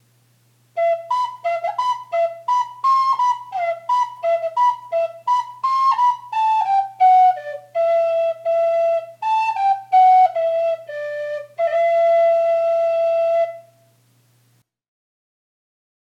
Flute Test
First, I record the audio clip in MasterTracks DAW and email it to Dropbox using the free Send to Dropbox service.
Flute2.m4a